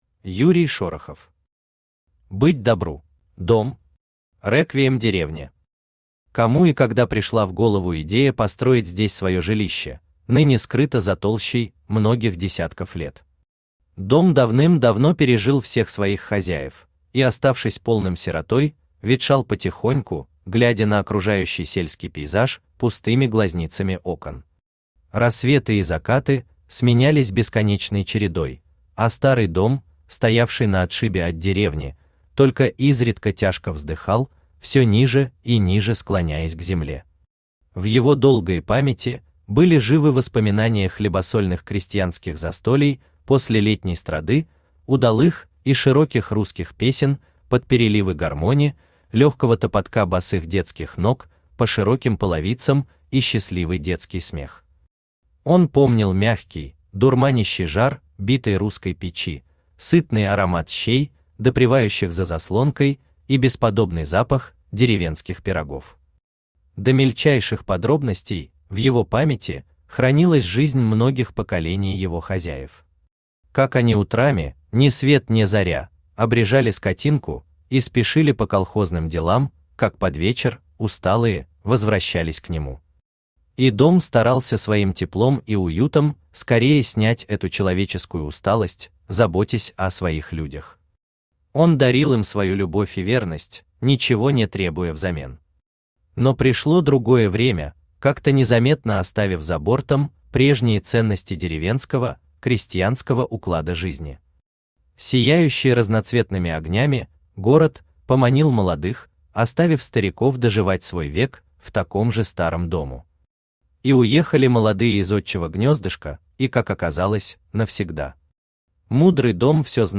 Aудиокнига Быть добру! Автор Юрий Шорохов Читает аудиокнигу Авточтец ЛитРес.